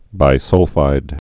(bī-sŭlfīd)